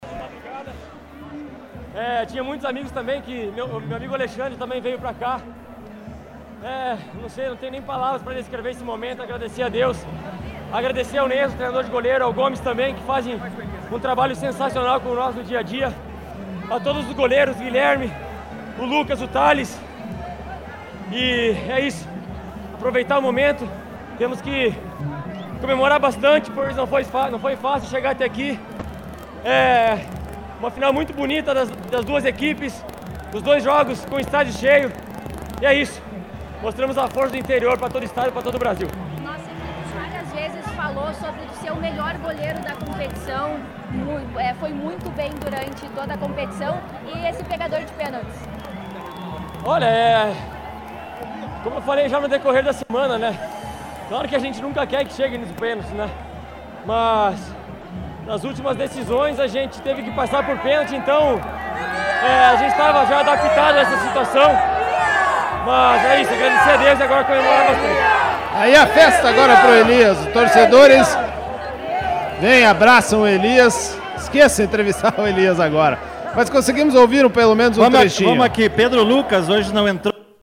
Na festa do título